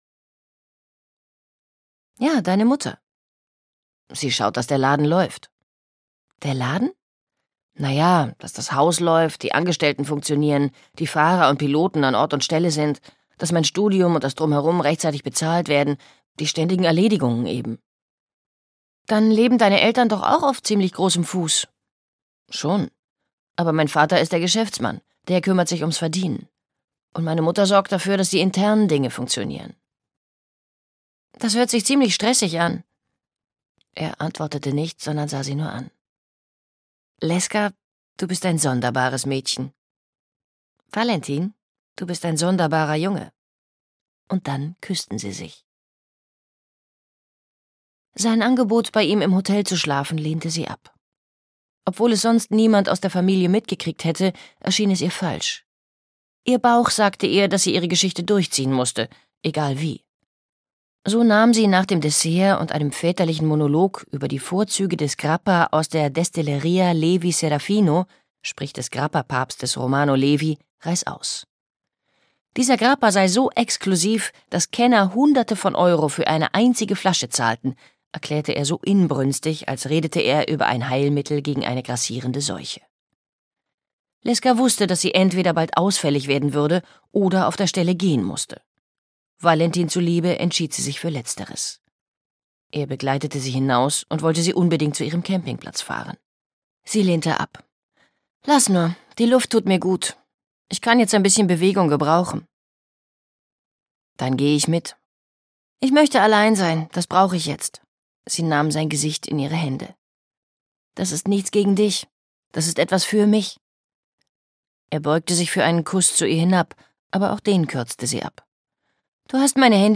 Zeig mir, was Liebe ist - Gaby Hauptmann - Hörbuch